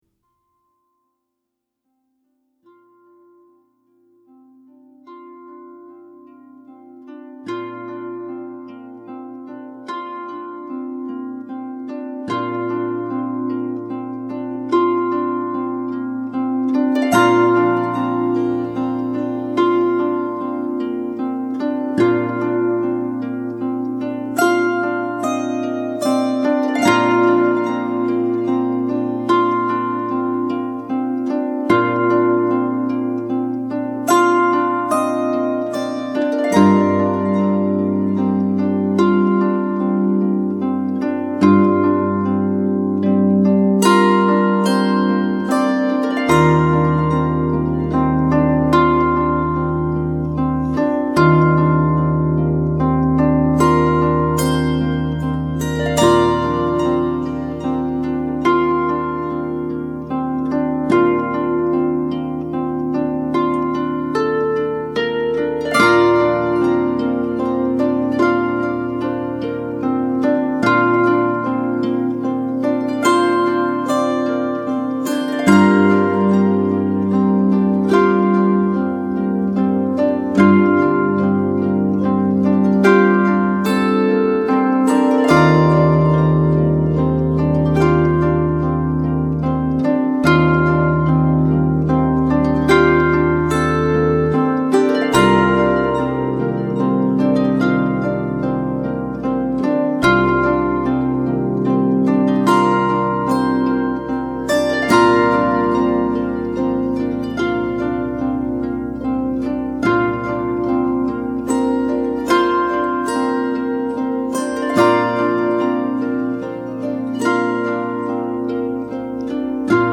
Intimate music for solo harp.